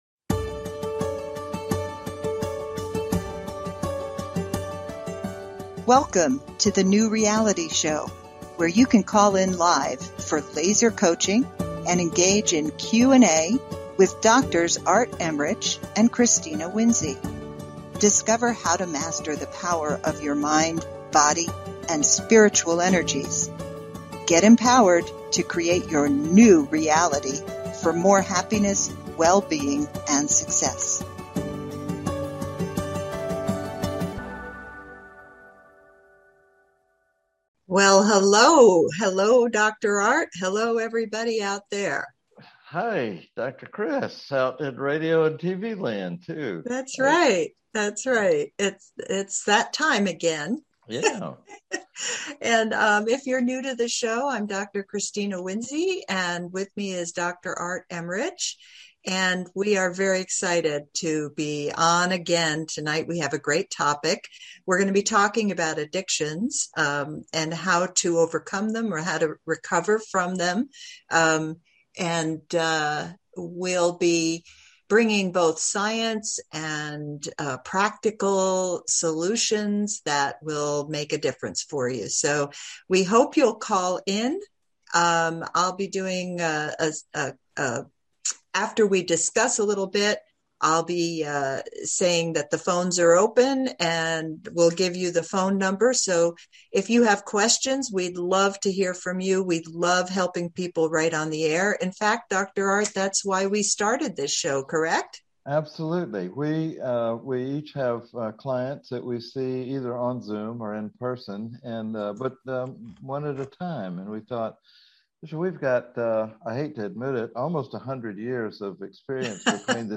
Talk Show Episode, Audio Podcast, HELP!